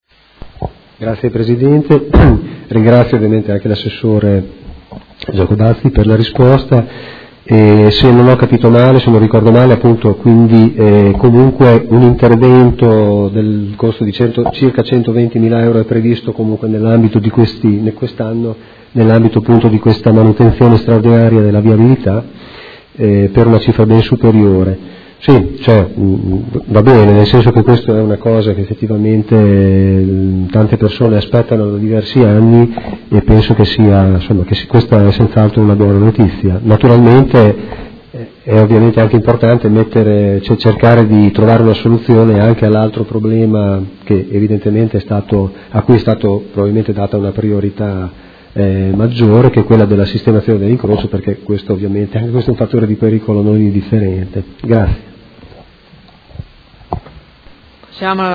Seduta del 11/05/2015 Replica a risposta Assessore Giacobazzi. Interrogazione dei Consiglieri Malferrari e Rocco (Art.1-MDP) avente per oggetto: Pista ciclopedonale in Via Rodolfo Gelmini interrotta e ammalorata da alcuni anni.